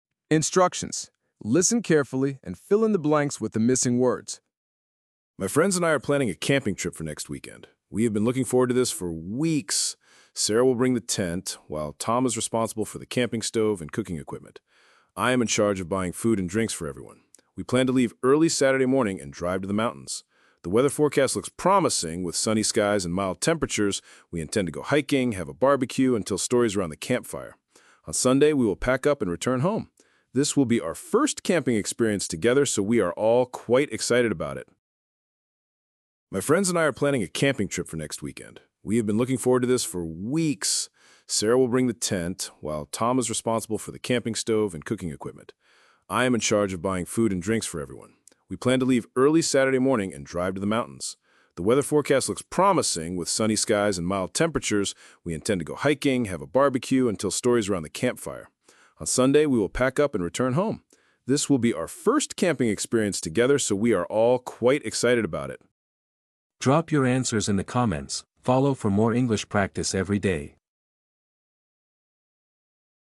Train your ears and boost your vocabulary with this short, engaging listening exercise!